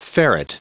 Prononciation du mot ferret en anglais (fichier audio)
Prononciation du mot : ferret